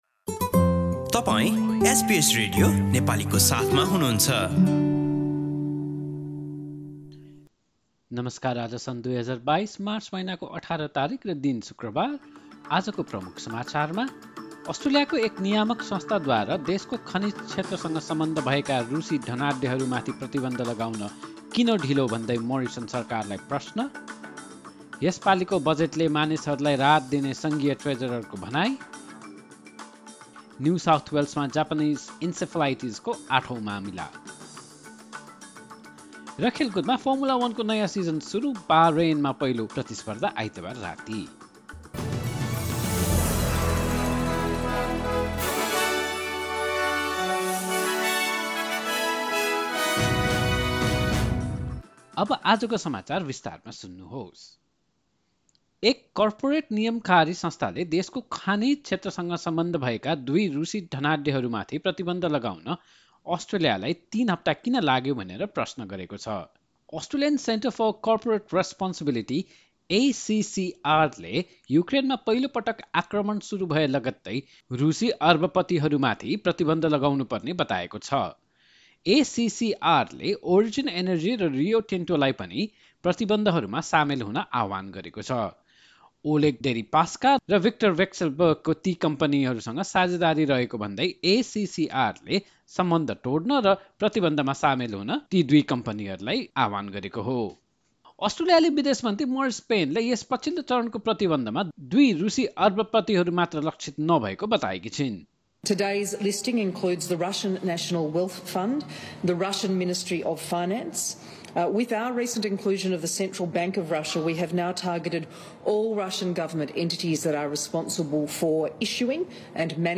Listen to the latest news headlines from Australia in Nepali. In this bulletin, a corporate watchdog questions why it took Australia three weeks to sanction two Russian oligarchs with ties to the Australian resource sector, the Federal Treasurer says next week's budget will deliver "targeted" cost-of-living relief for Australians and New South Wales records the eighth case of Japanese Encephalitis.